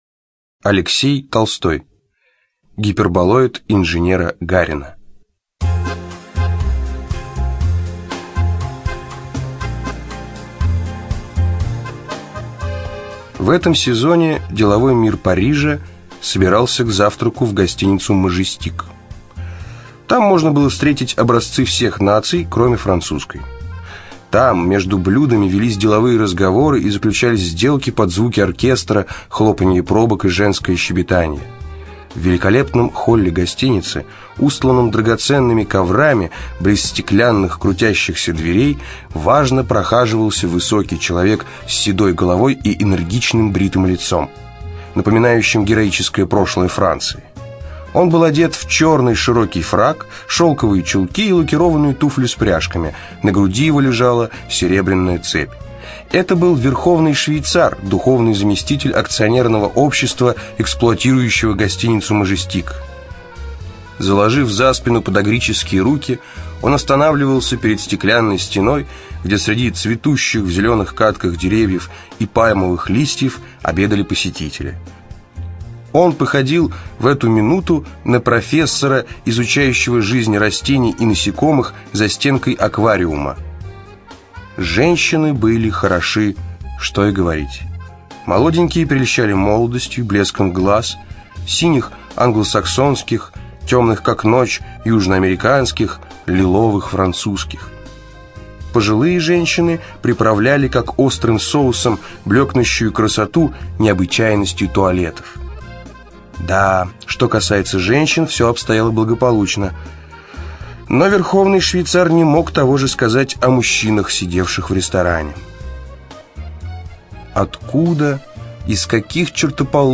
Студия звукозаписиСидиком